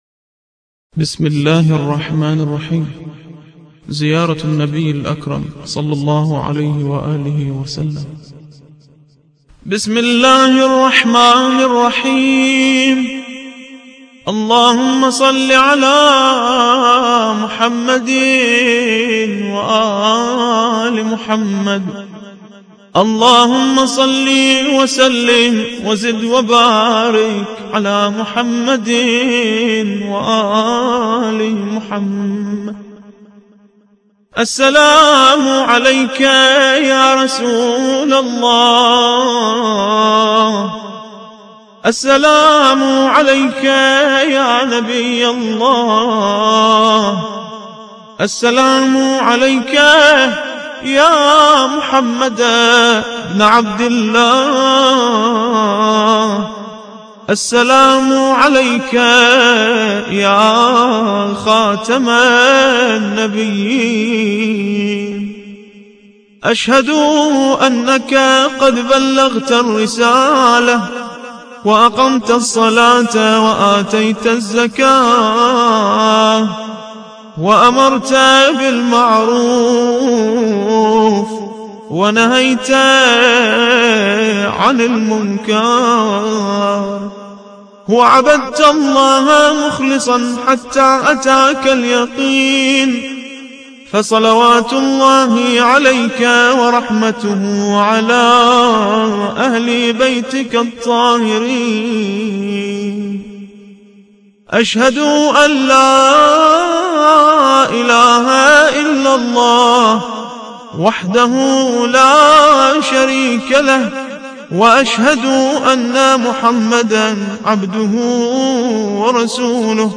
زيارة النبي الأكرم صلى الله عليه وآله